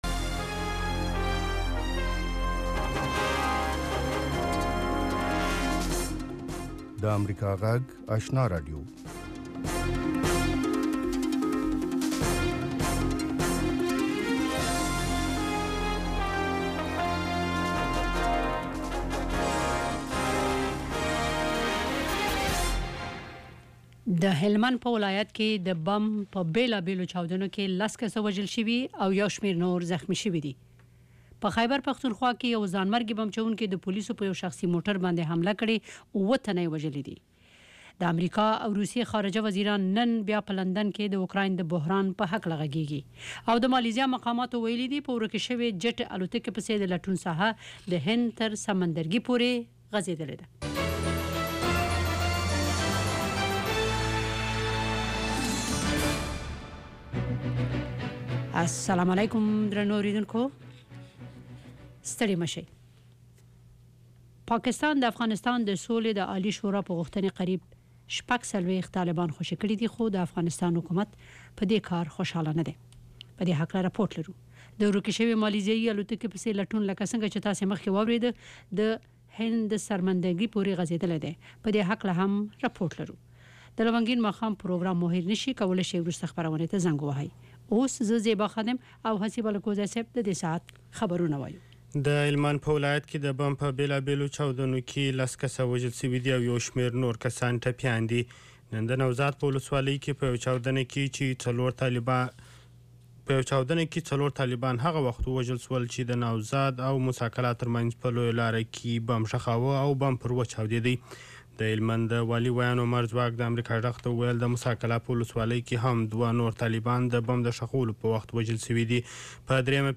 یو ساعته خپرونه: تازه خبرونه، د ځوانانو، میرمنو، روغتیا، ستاسو غږ، ساینس او ټیکنالوژي، سندرو او ادب په هکله اونیز پروگرامونه.